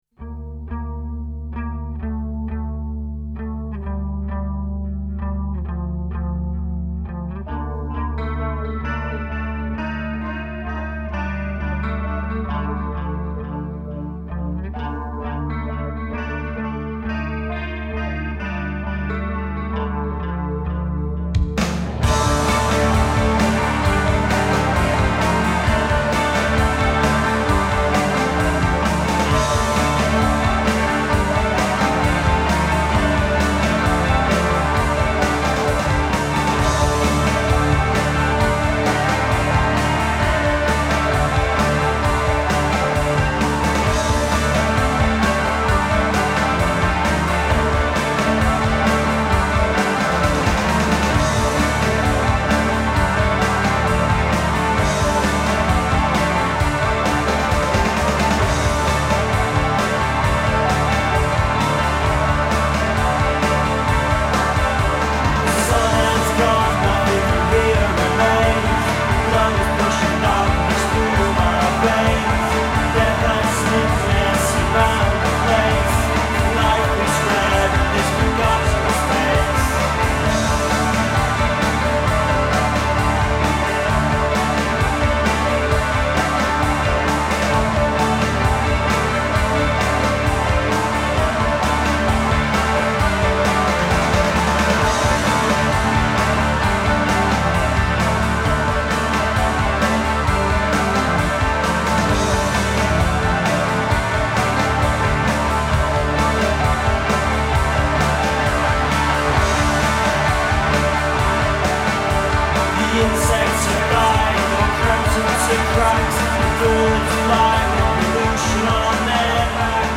Psychedelia and modernity.